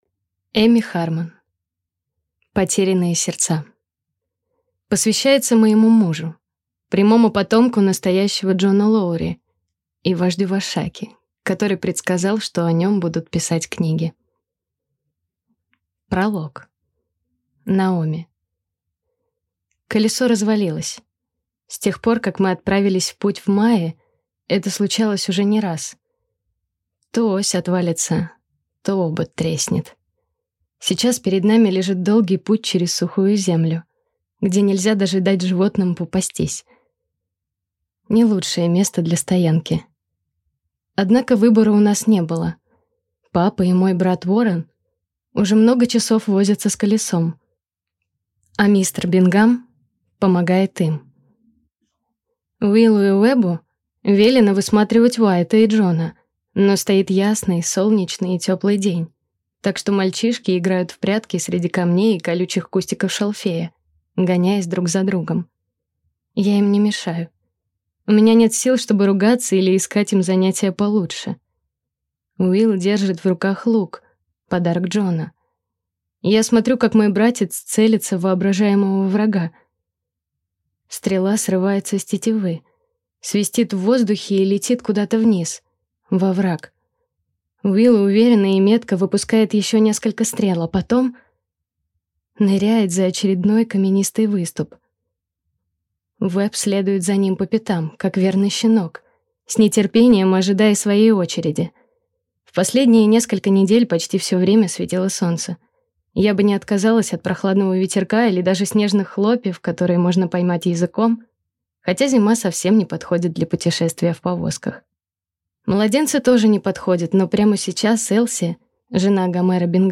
Аудиокнига Потерянные сердца | Библиотека аудиокниг
Прослушать и бесплатно скачать фрагмент аудиокниги